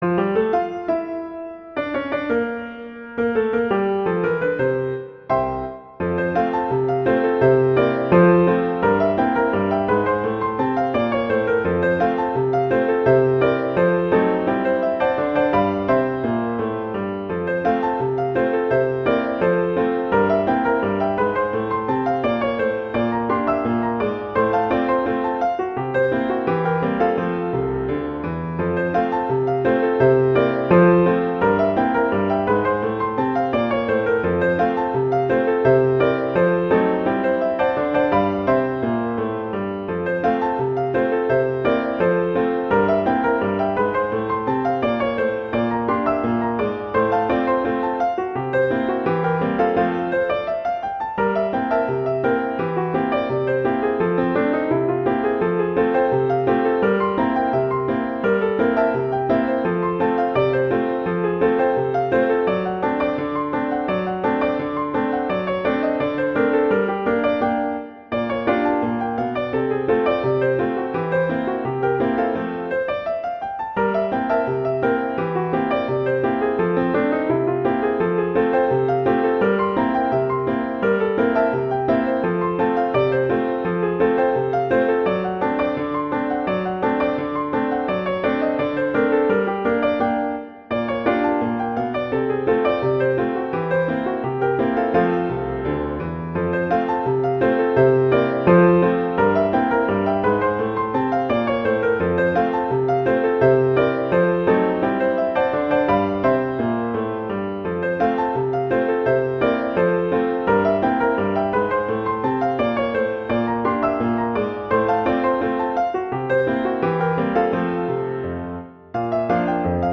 jazz, classical, multicultural